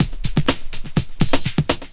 21 LOOP06 -R.wav